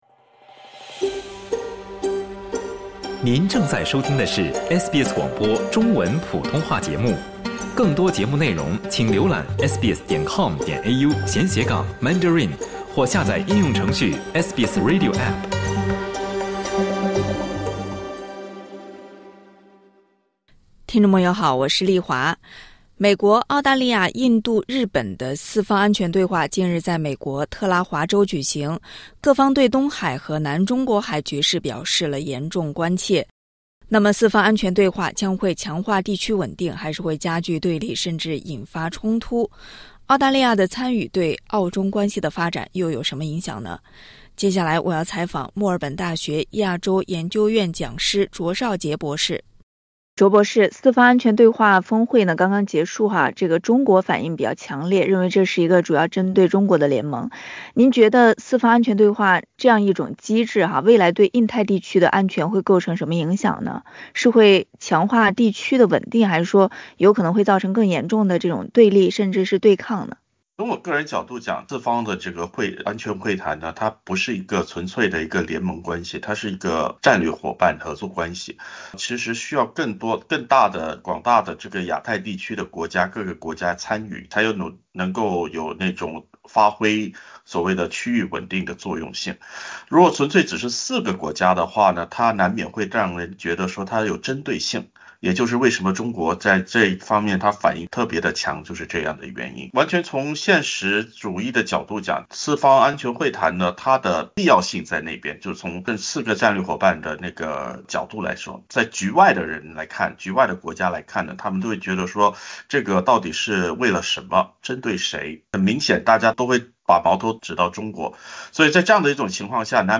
“四方安全对话”将会强化地区稳定还是会加剧对立甚至引发冲突？点击音频，收听完整采访。